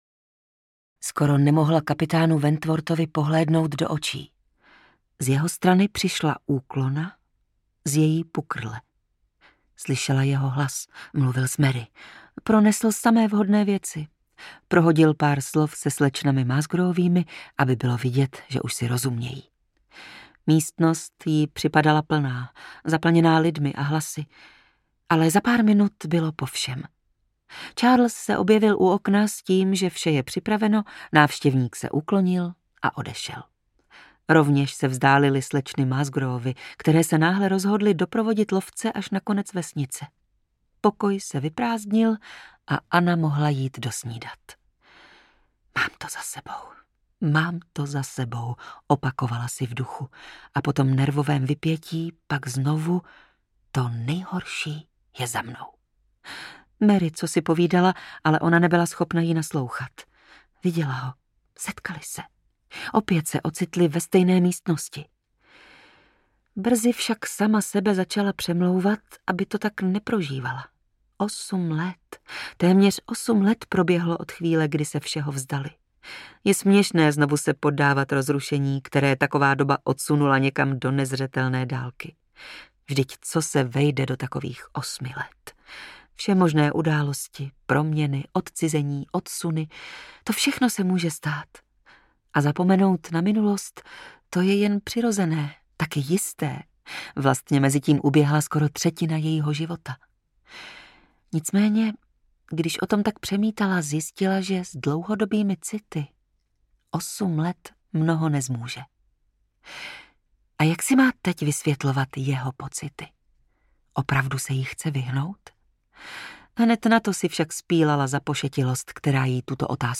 Anna Elliotová audiokniha
Ukázka z knihy
Čte Dana Černá.
Vyrobilo studio Soundgru.